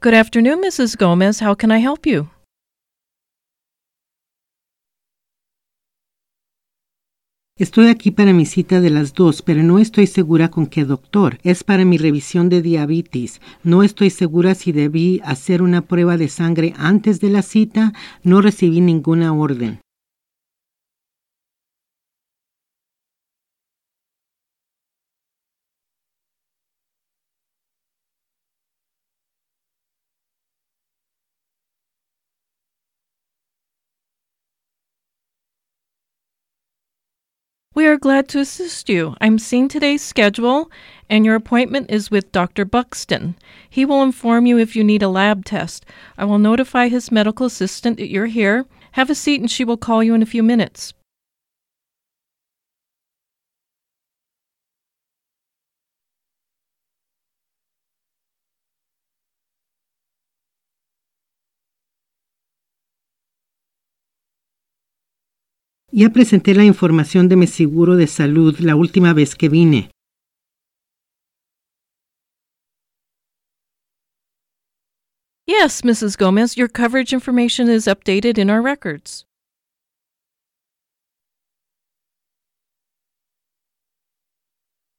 VCI-Practice-Dialogue-14-Receptionist-EN-SP.mp3